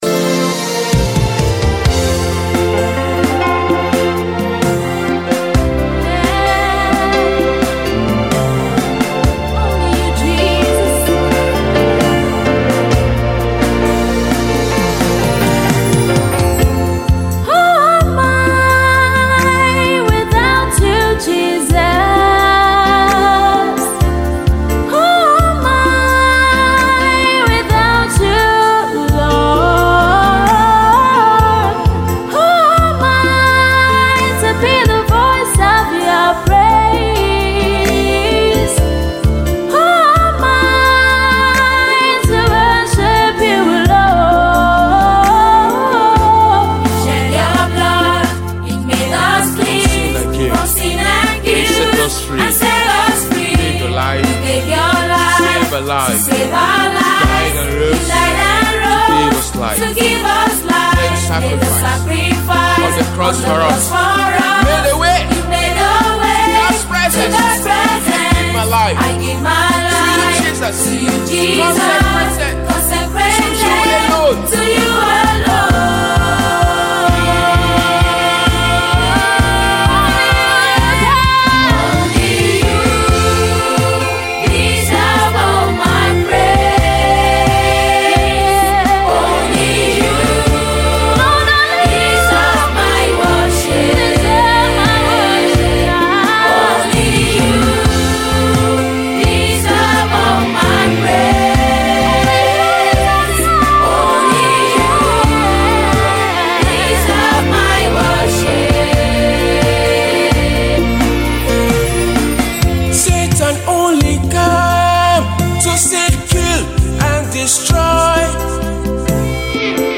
LYRICSmusicWorship
is a powerful song of surrender and devotion
Keyboards and Percussions
Guitars
Backup singers